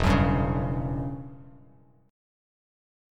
Em6add9 chord